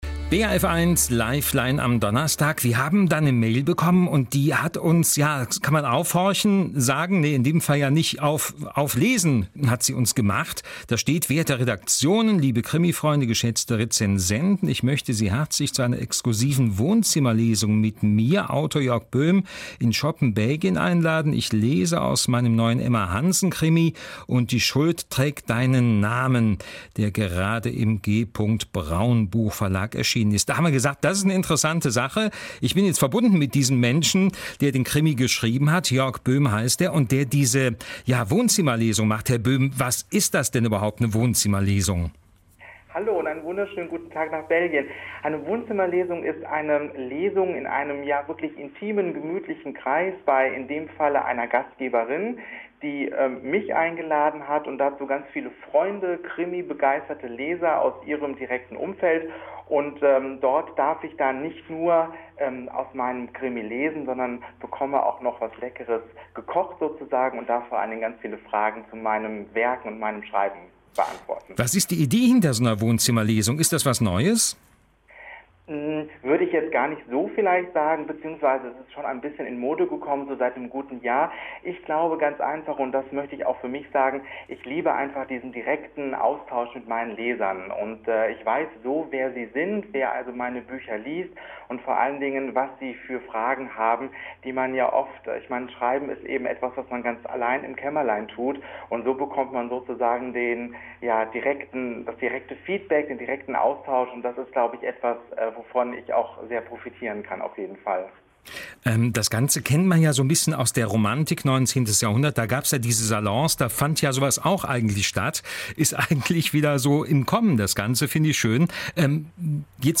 Hier der Mitschnitt aus der Live-Sendung von BRF 1 am 14. März 2014.